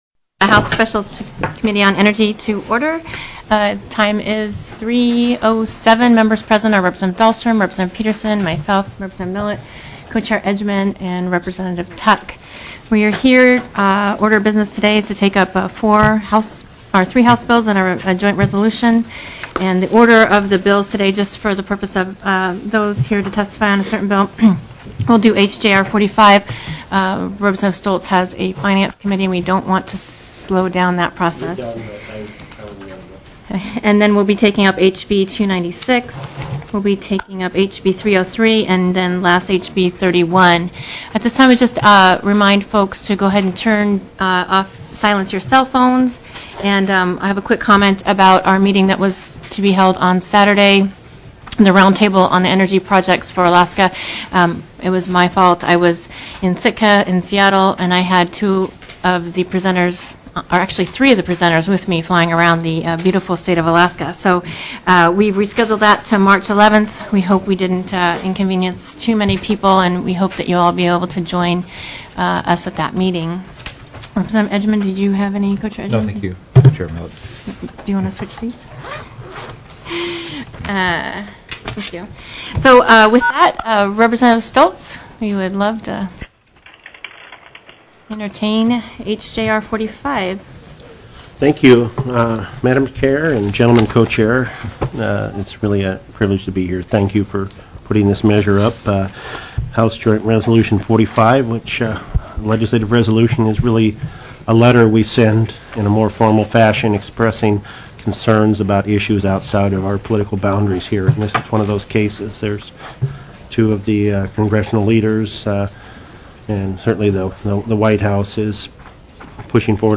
02/23/2010 03:00 PM House ENERGY